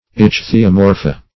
Search Result for " ichthyomorpha" : The Collaborative International Dictionary of English v.0.48: Ichthyomorpha \Ich`thy*o*mor"pha\, n. pl.